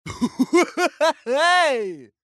На этой странице собраны разнообразные звуки клоунов: от смеха и шуток до неожиданных скрипов и хлопушек.
Добрый смех клоуна